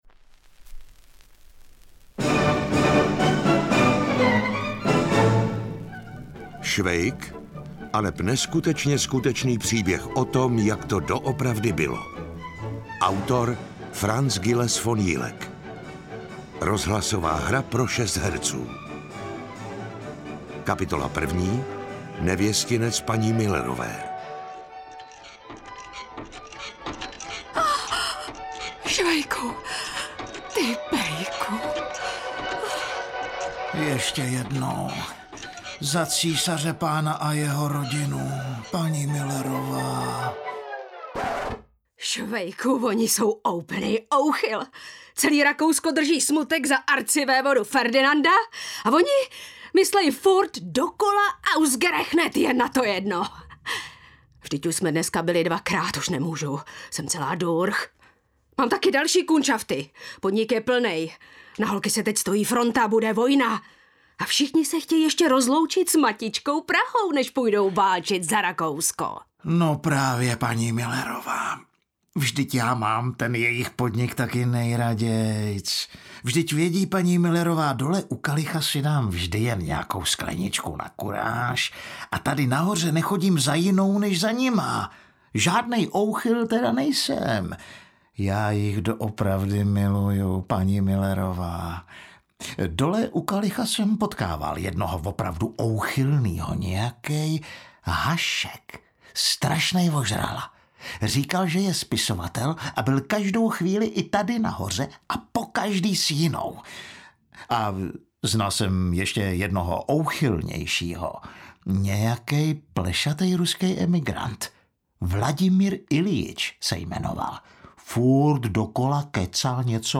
Tato exkluzivní kolekce pěti audioknih Vás provede uličkami staré Prahy, kde se pod leskem císařského majestátu odehrávaly ty nejzábavnější příběhy, plné lidskosti a typicky českého humoru, který dávno dobyl celý svět.